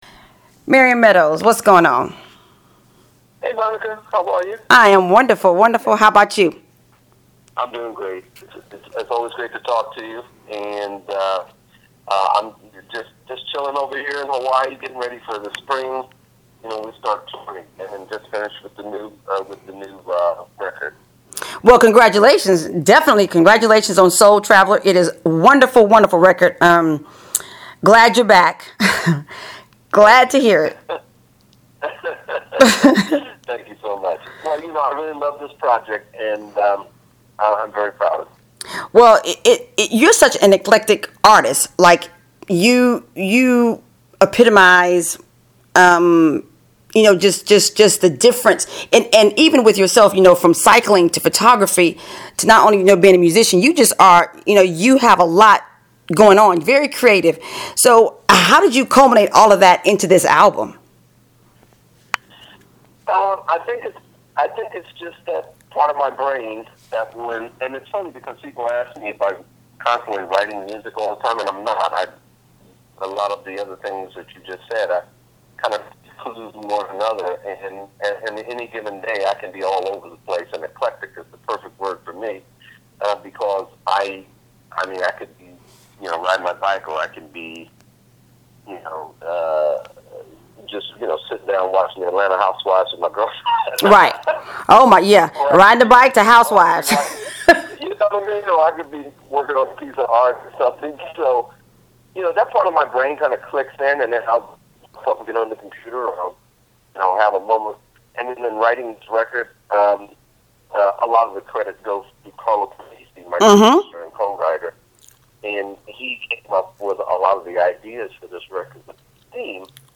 Listen as Marion and I talk all things “Soul Traveler”: